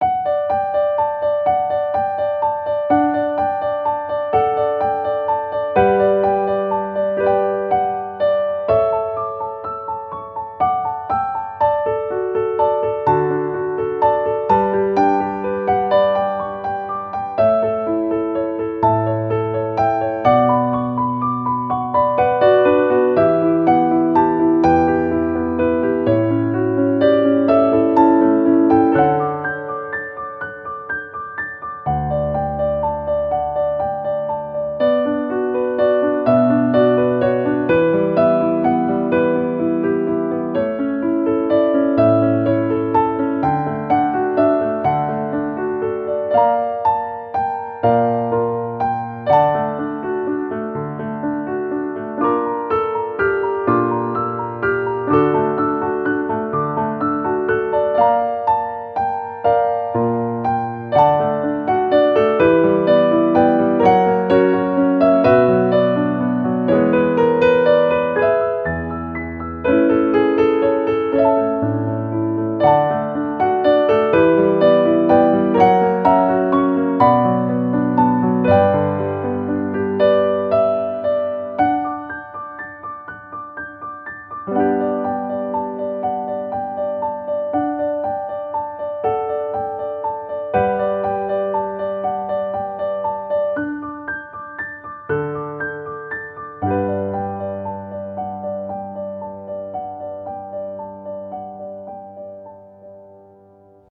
ogg(L) - しっとり 流麗 夜空